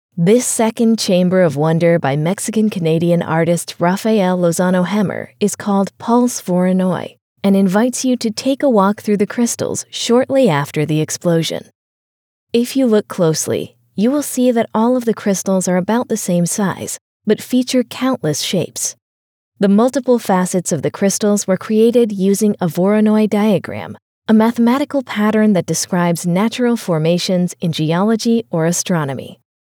Englisch (Amerikanisch)
Kommerziell, Natürlich, Freundlich, Warm, Corporate
Audioguide